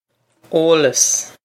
eolas oh-los
Pronunciation for how to say
This is an approximate phonetic pronunciation of the phrase.